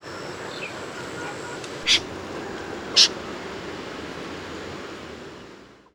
Звуки бекаса
На этой странице собраны звуки бекаса — редкой болотной птицы с характерным «блеянием».
Звук на фоне азиатского бекаса